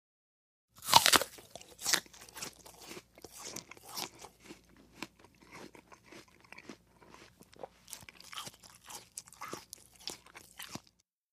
BiteAppleChew PE677806
DINING - KITCHENS & EATING APPLE: INT: Bite into apple & chew.